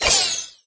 sandy_no_ammo_01.ogg